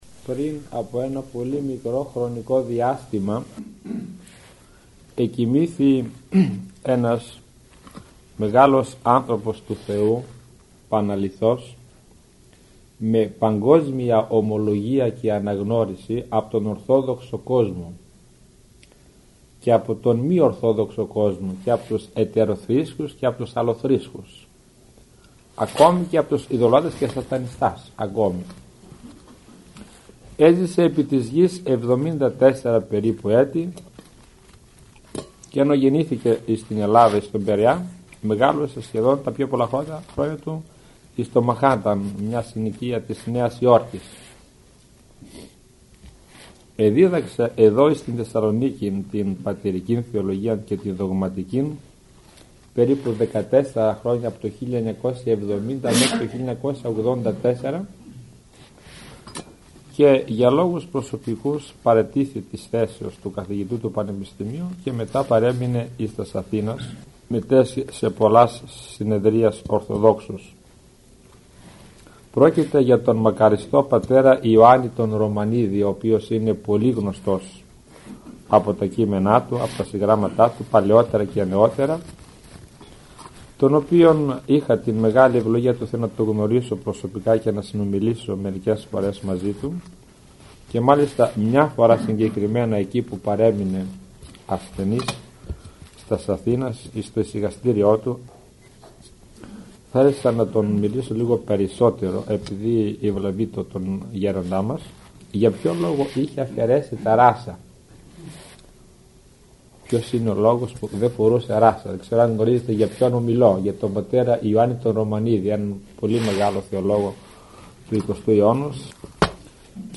Ακολούθως σας παραθέτουμε ηχογραφημένη ομιλία του Πανοσ.